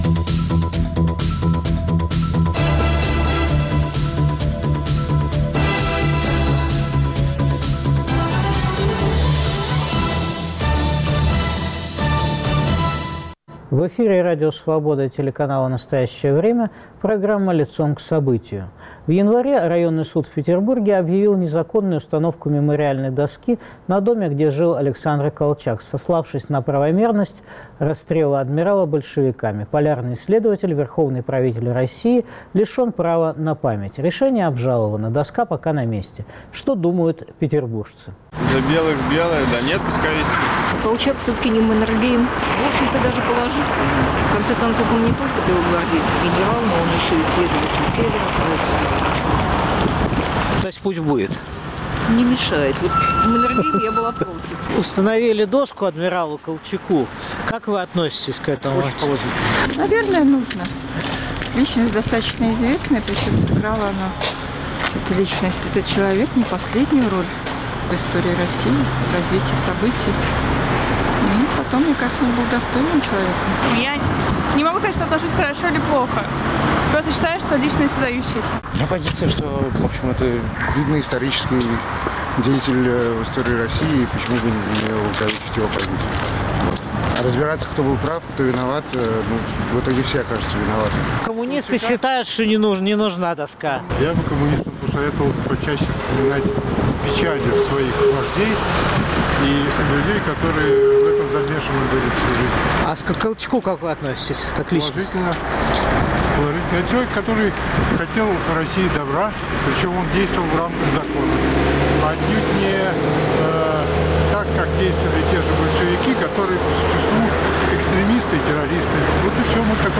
Беседа о судьбе реформ в России с писателем-историком Яковом Гординым.